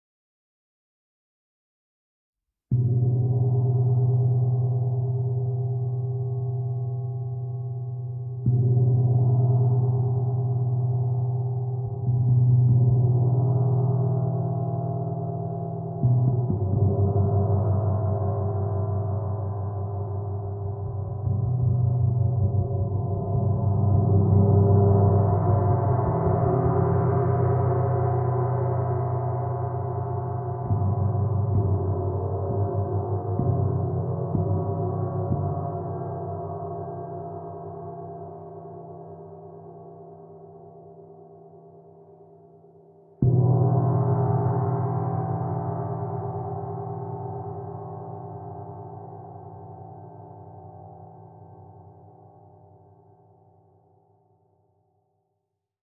Meinl Sonic Energy 22" Soundscape Gong, Moon Phases (SGMP22)
Feature: Rich, dynamic soundscapeFeature: Polished surface for a luxurious shineFeature: Easily playable edgeFeature: High-quality engraved symbolFea…